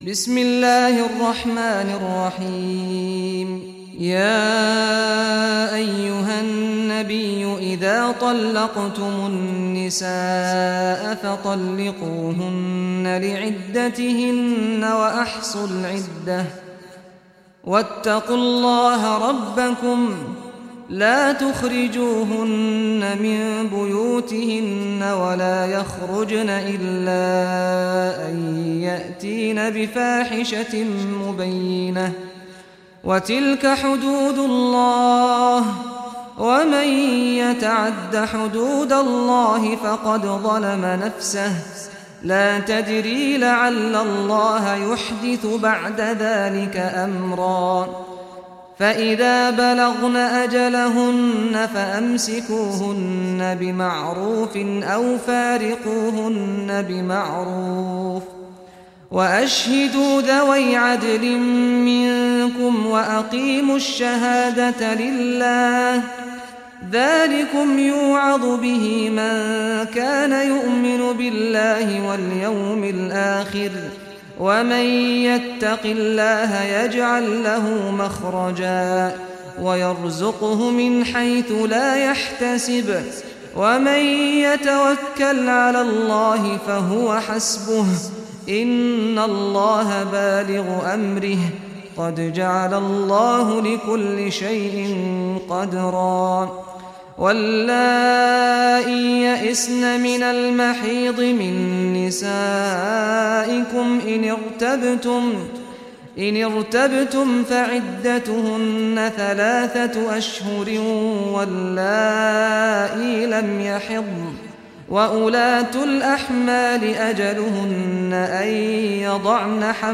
Surah At-Talaq Recitation by Sheikh Saad al Ghamdi
Surah At-Talaq, listen or play online mp3 tilawat / recitation in Arabic in the beautiful voice of Sheikh Saad al Ghamdi.